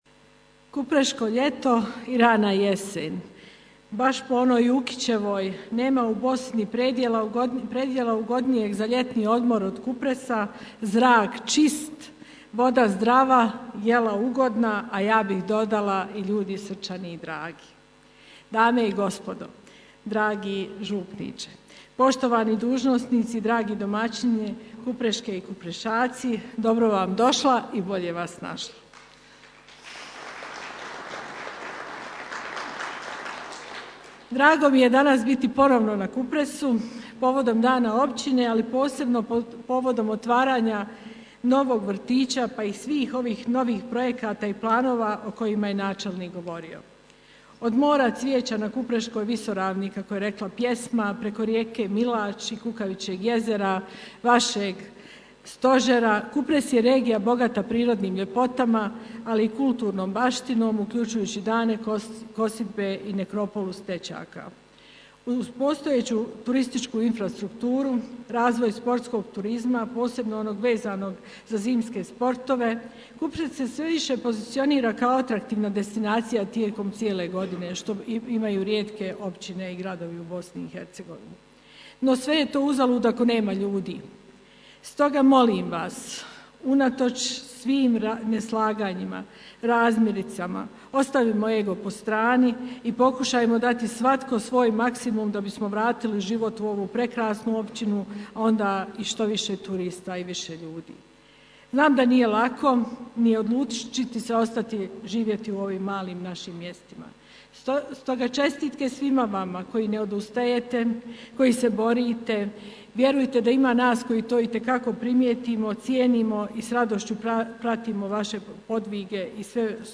Prigodan govor izrekla je i predsjednica FBiH gđa. Lidija Bradara: